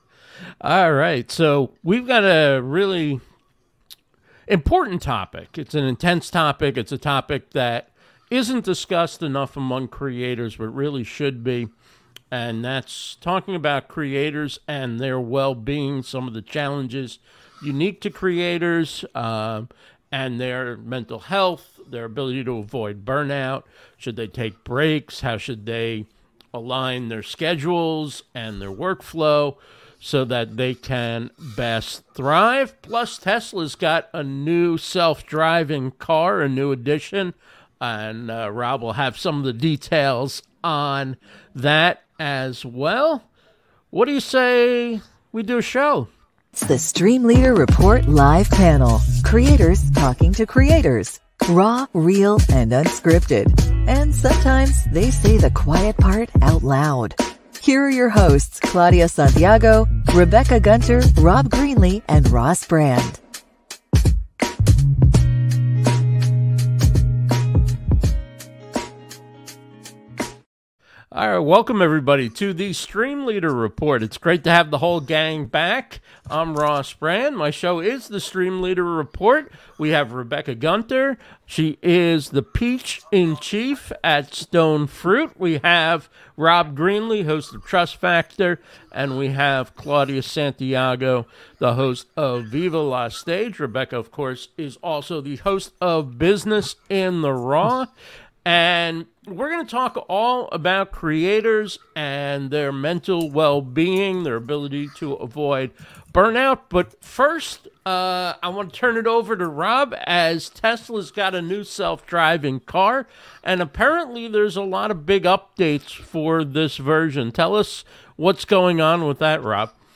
StreamLeader Report Live Panel is livestreamed & recorded with StreamYard.